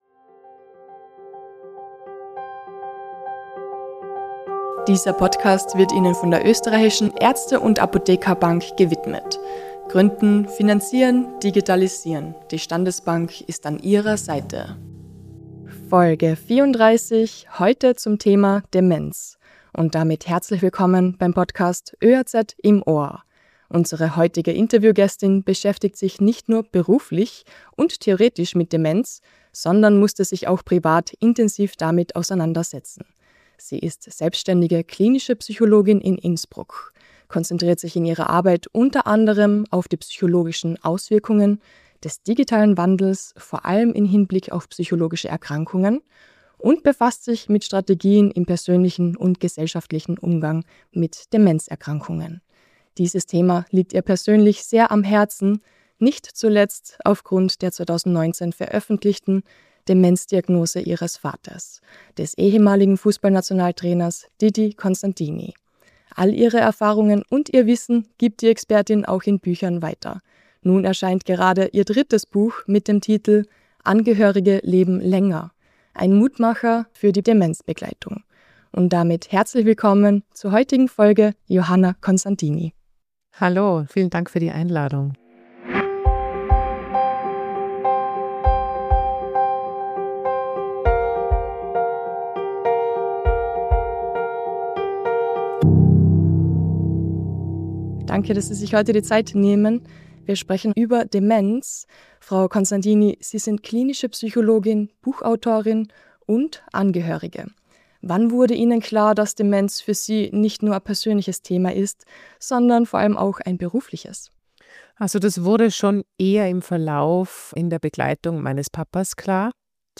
Welchen Einfluss die Demenz-Diagnose auf Sie und Ihre Familie hatte, wie Sie damit umgingen, welche Tipps Sie für Angehörige hat und was Sie sich im Umgang mit Demenzpatient:innen an der Tara von Apotheker:innen wünscht, erklärt Sie in diesem Gespräch.